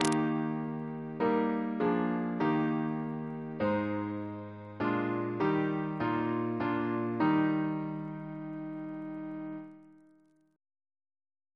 Single chant in E♭ Composer: Philip Hayes (1738-1797) Reference psalters: OCB: 34